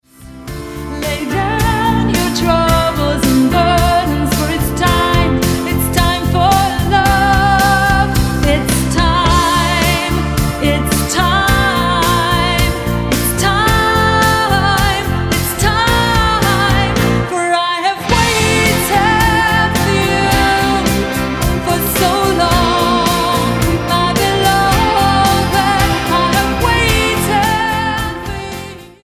STYLE: Celtic
guitar lifting the song